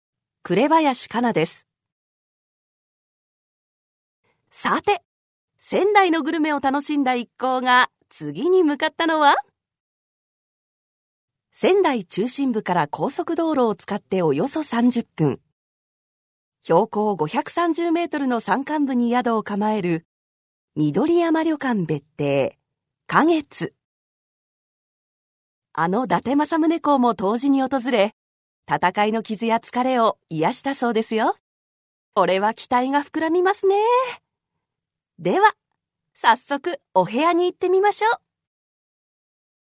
Voice Sample
ナレーション1